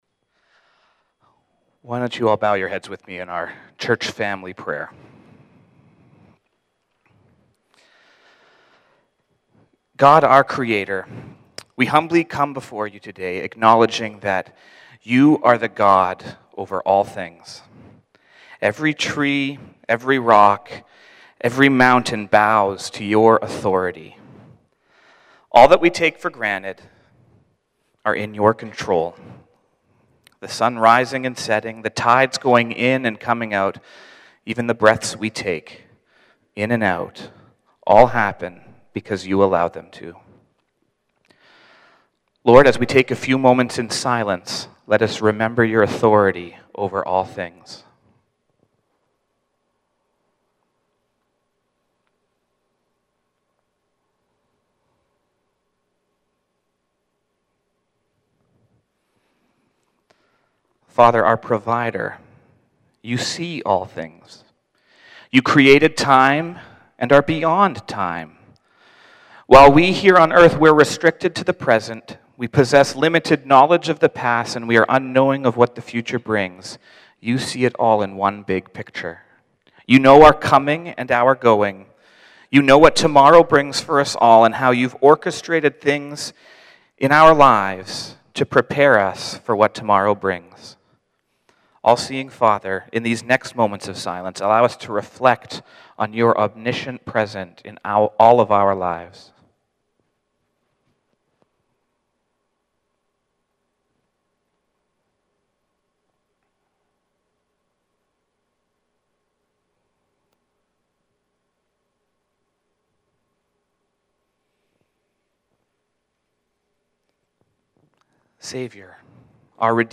Sermons | Langley Immanuel Christian Reformed Church